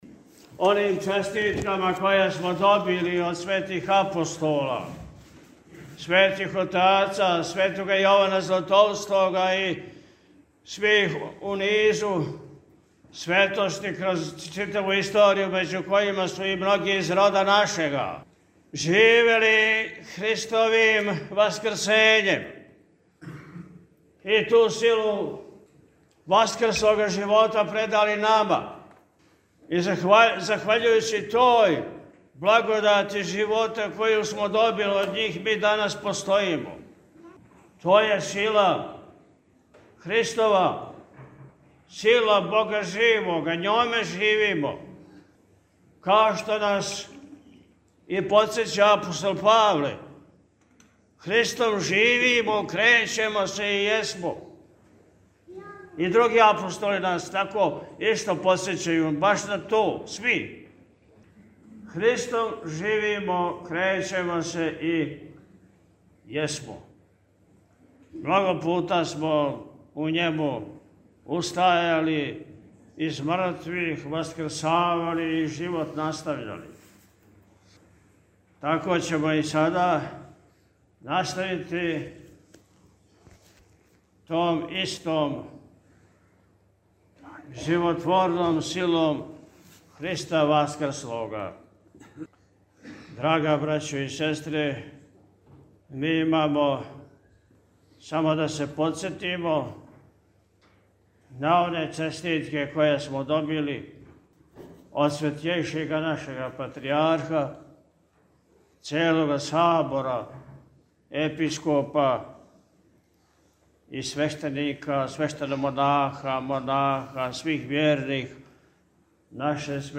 На највећи хришћански празник, Светло Васкрсење Христово, 20. априла 2025. године, Његово Високопреосвештенство Архиепископ и Митрополит милешевски г. Атанасије служио је Васкршњу Литургију у Вазнесењском храму манастира Милешеве.
Након прочитане Васкршње Посланице Патријарха српског Порфирија и свих Архијереја СПЦ, а на крају службе, Митрополит Атанасије је, честитајући сабранима највећи празник, кратко упутио речи пастирске поуке: – Захваљујући сили Васкрслога Христа, коју смо добили од Светих Апостола, Светих Отаца, од којих и многих из рода нашега, захваљујући тој благодати живота коју смо добили од њих, ми данас постојимо.